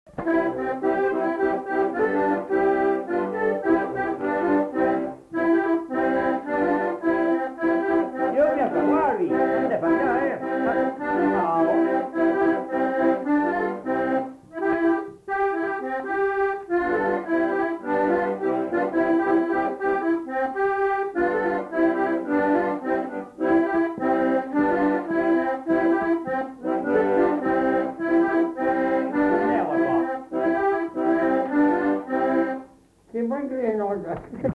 interprété à l'accordéon diatonique
enquêtes sonores